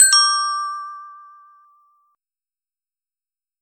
Alarm Triangle.mp3